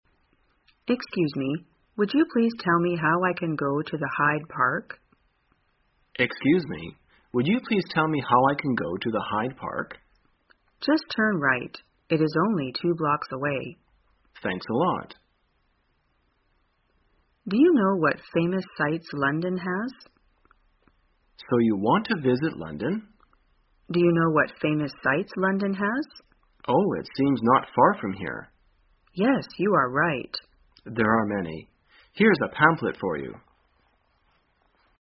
在线英语听力室生活口语天天说 第337期:怎样询问英国的名胜的听力文件下载,《生活口语天天说》栏目将日常生活中最常用到的口语句型进行收集和重点讲解。真人发音配字幕帮助英语爱好者们练习听力并进行口语跟读。